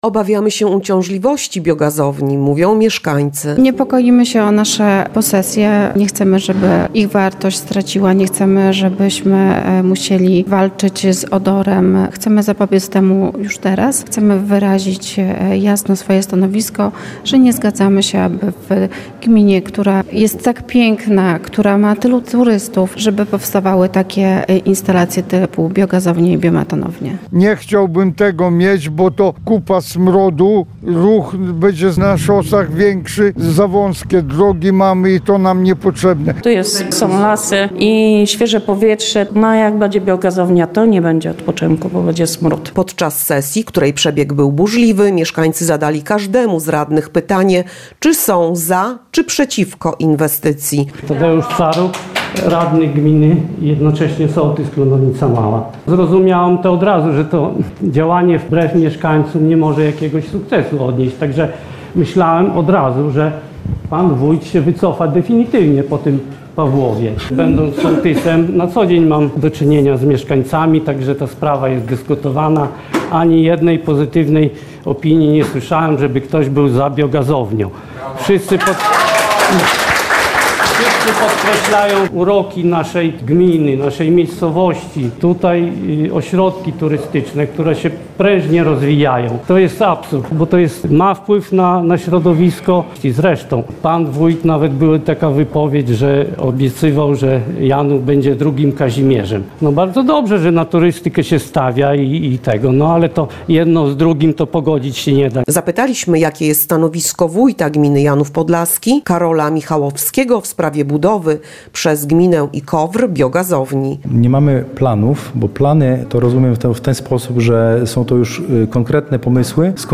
Taka informacja poruszyła mieszkańców gminy, którzy licznie przybyli na ostatnią sesję rady gminy, by wyrazić swój sprzeciw.